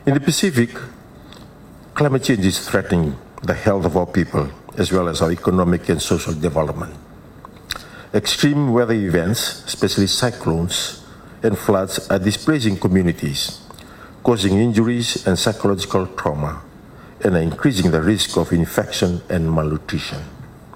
Speaking at the opening of the Pacific Regional Forum on the National Human Rights Institution in Nadi, Turaga says climate change is a shared threat for many countries in the region.